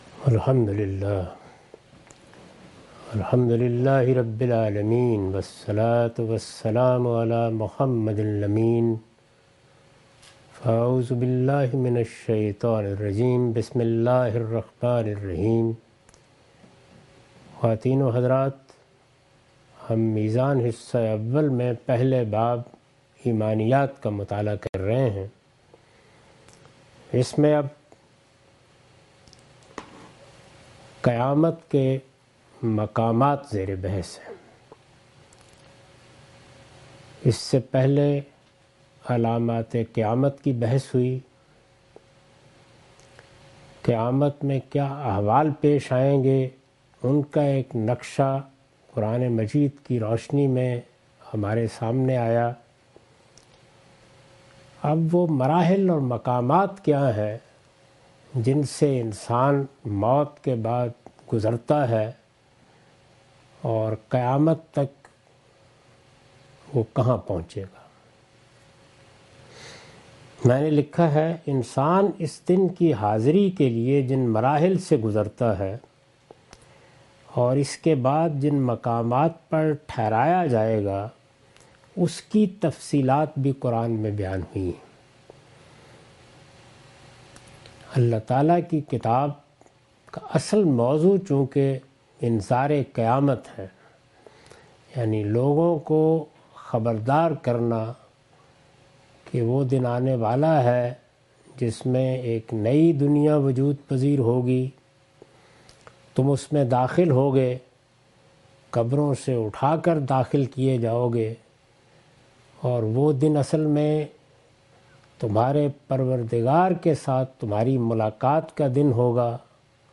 Meezan Class by Javed Ahmad Ghamidi.